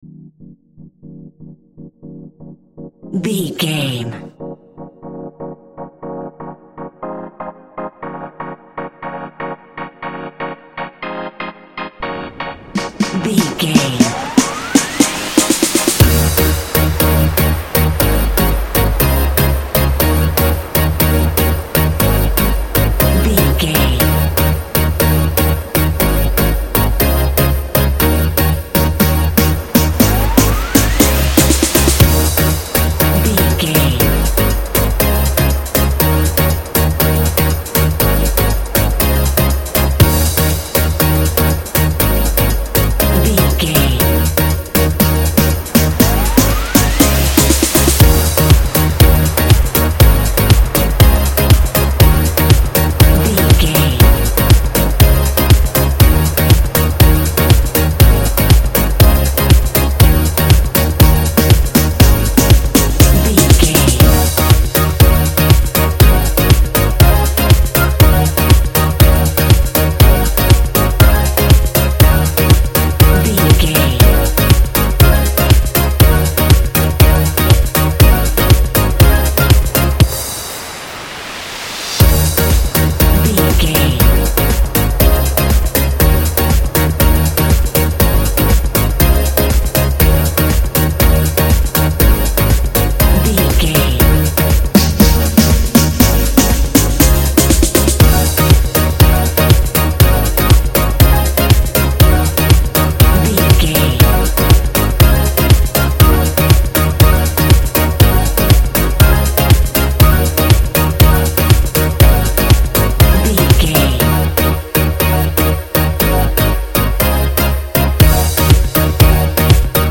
Disco Tech Funk Groove.
Ionian/Major
D♭
groovy
uplifting
futuristic
driving
energetic
drums
synthesiser
bass guitar
funky house
nu disco
upbeat
funky guitar
fender rhodes
horns